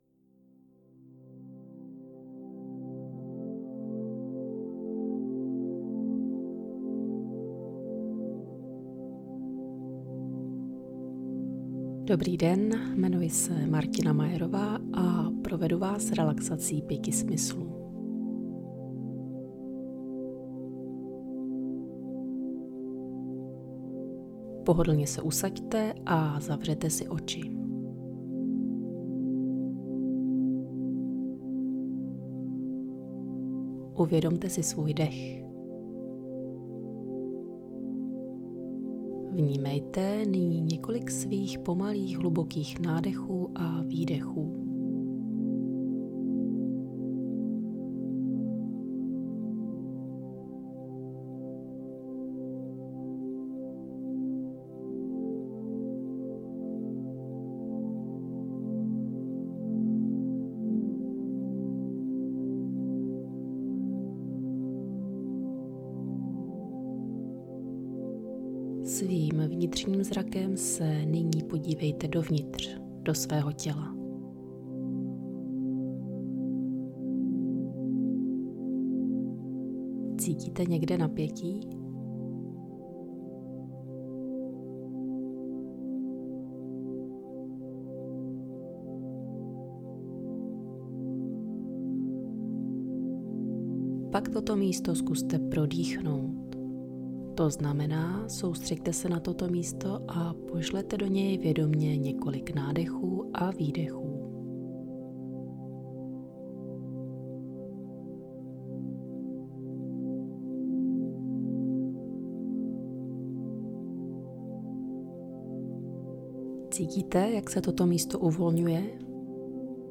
V jednom místě se nahrávka záměrně téměř úplně ztiší - nenechte se tím prosím vyrušit.
RELAX.mp3